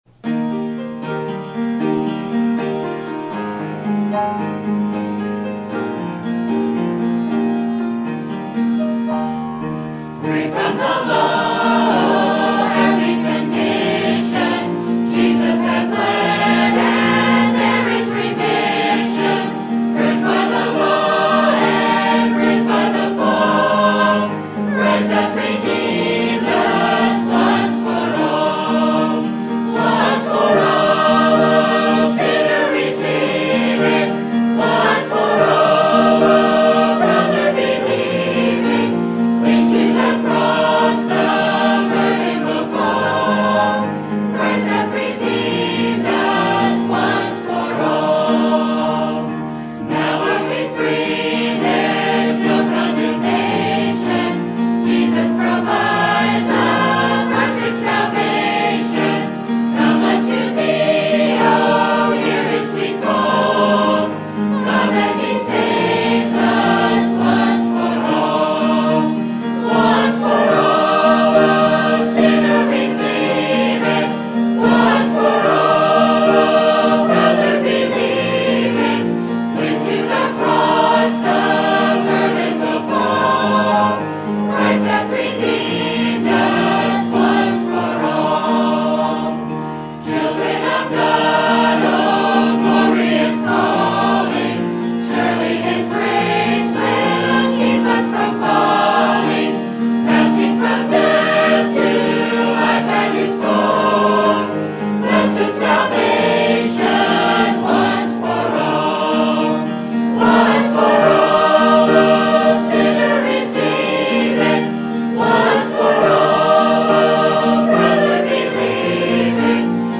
Sermon Date: Sunday, April 10, 2016 - 11:00am Sermon Title: How Will You Plea Before The Judge Of Heaven?